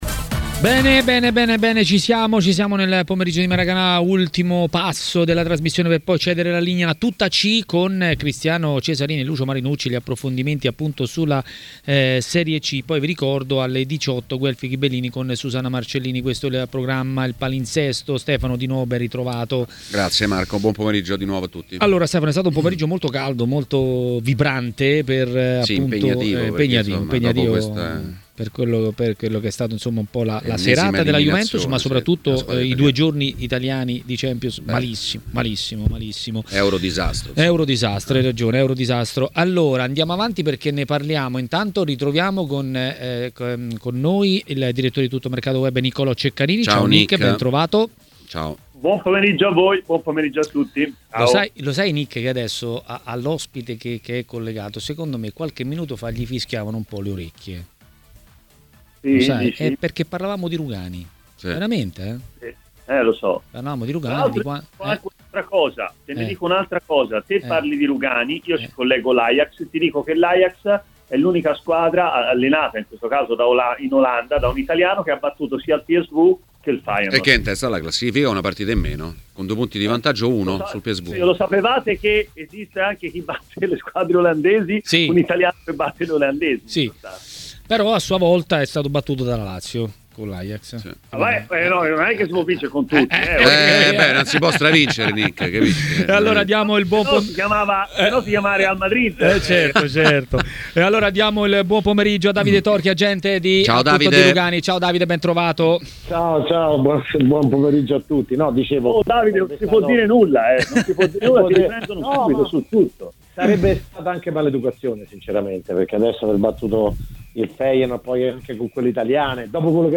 … continue reading 38 Episoden # Notizie sportive # TMW Radio # Calcio # Diretta # Calciomercato # Cronaca # Interviste # Sport # segnalazioni # WEBRADIO